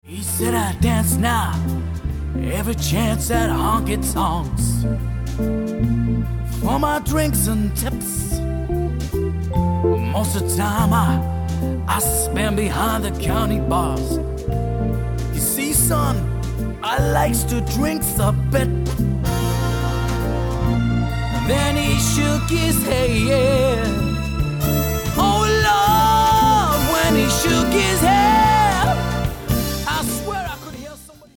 • Can perform solo to backing tracks or with full live band